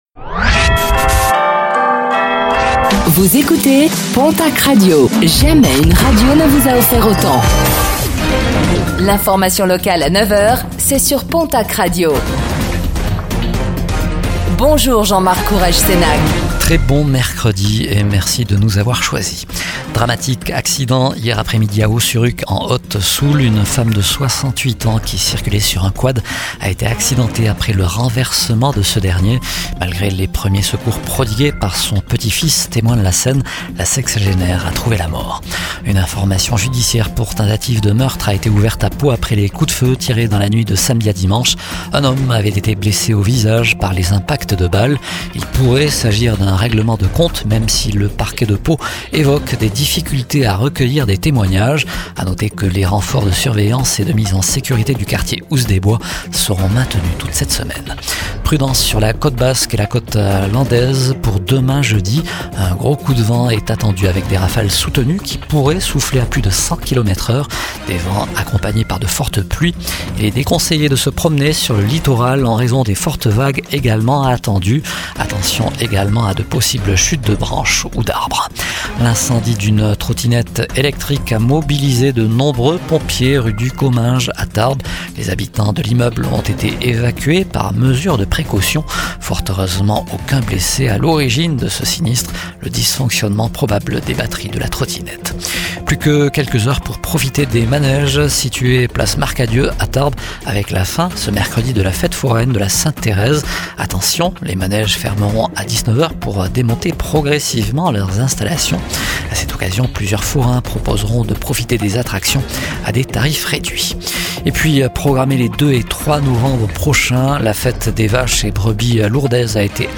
09:05 Écouter le podcast Télécharger le podcast Réécoutez le flash d'information locale de ce mercredi 22 octobre 2025